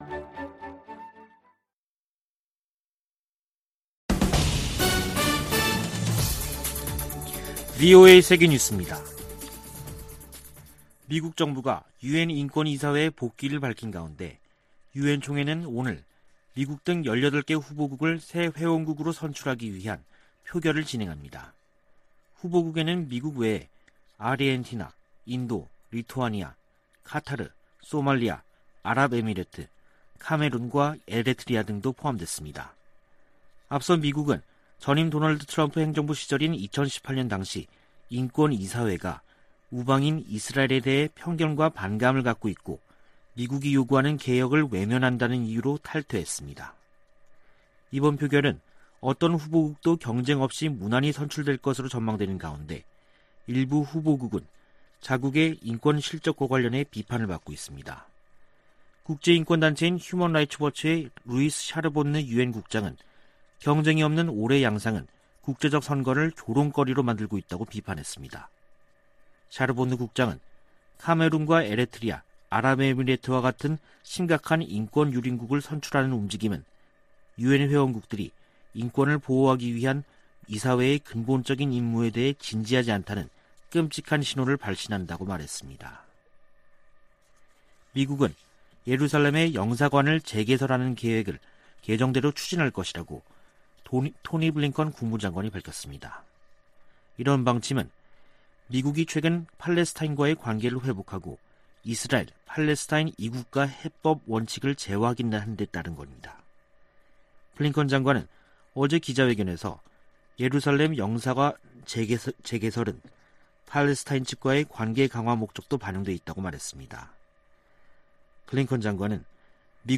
VOA 한국어 간판 뉴스 프로그램 '뉴스 투데이', 2021년 10월 14일 3부 방송입니다. 미 국가정보국장이 북한을 전통적인 위협으로 꼽았습니다. 미 공화당 의원들이 내년 11월 중간선거를 앞두고 조 바이든 대통령의 대외 정책을 비판하며 공세를 강화하고 있습니다. 한국 정부가 종전선언 문제를 거듭 제기하면서 미한 동맹의 북한 논의에서 주요 의제로 떠오르고 있습니다.